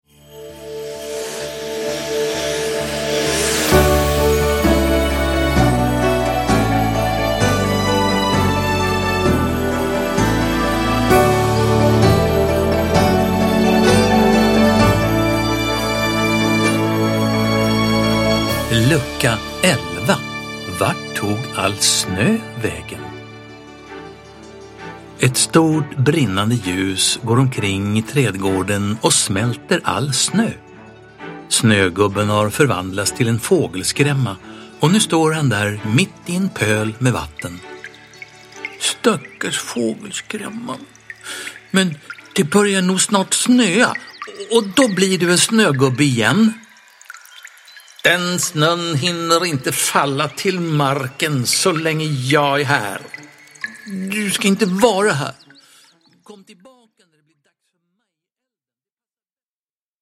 Humlan Djojjs Julkalender (Avsnitt 11) – Ljudbok – Laddas ner
Uppläsare: Staffan Götestam